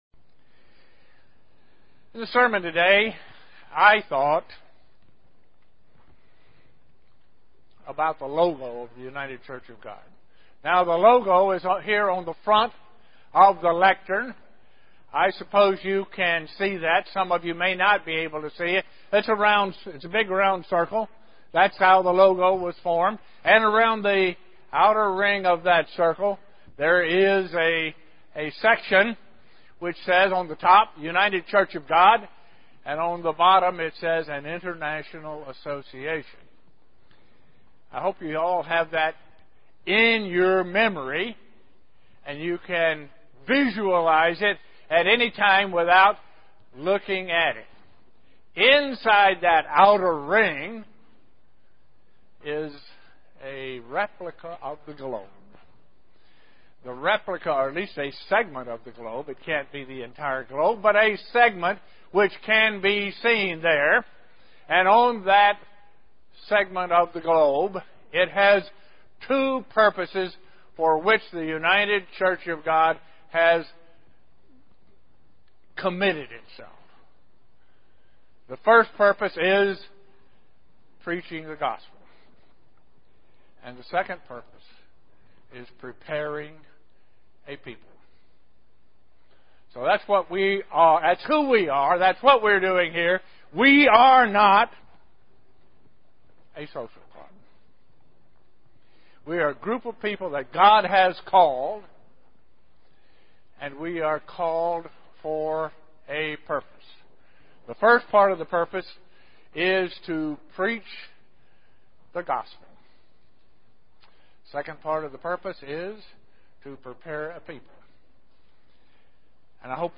We are being prepared to teach and guide during the millenium and beyond. This message was given on the Feast of Pentecost.
UCG Sermon Studying the bible?